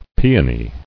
[pe·o·ny]